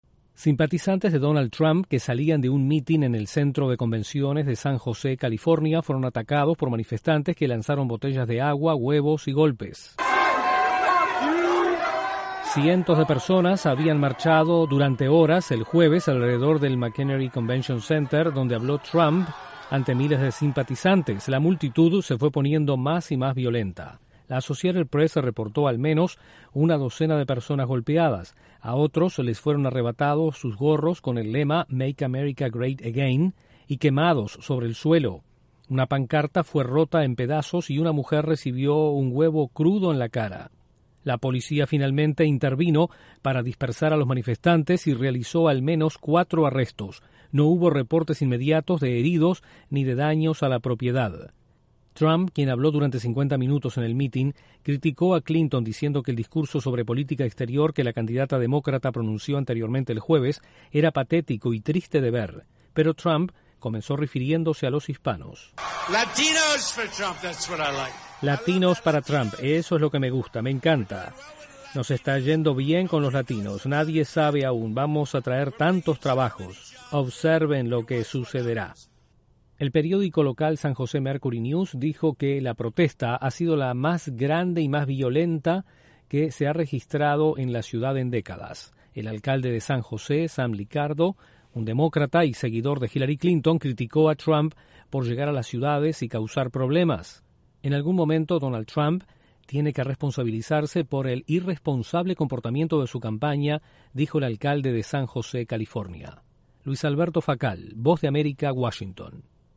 Manifestantes tomaron las calles de San José, California, tras un discurso de Donald Trump. Desde la Voz de América en Washington informa